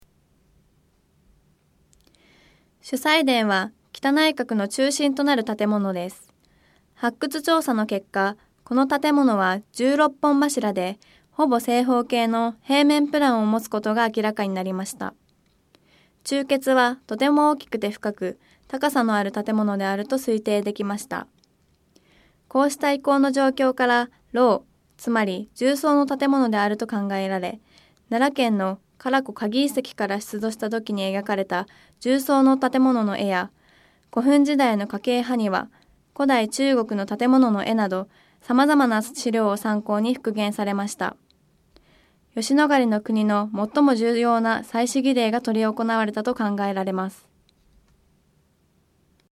吉野ヶ里のクニの最も重要な祭祀儀礼が執り行われたと考えられます。 音声ガイド 前のページ 次のページ ケータイガイドトップへ (C)YOSHINOGARI HISTORICAL PARK